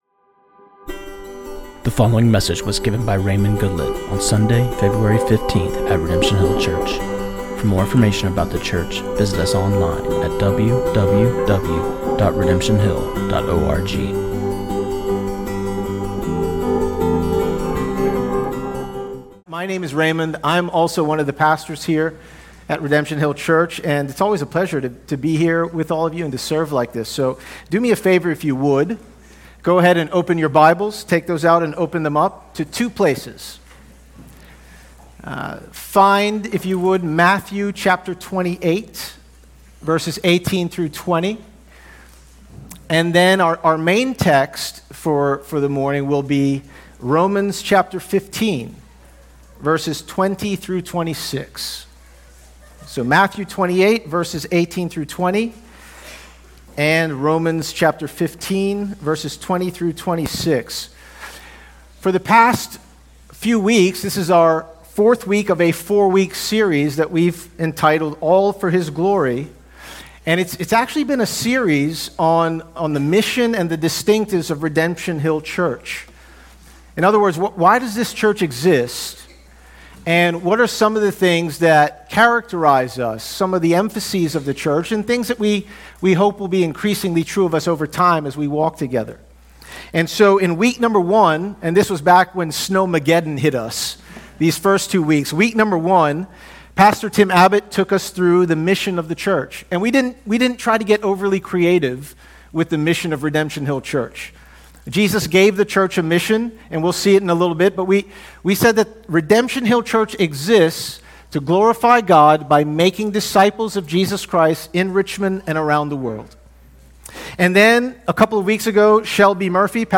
This sermon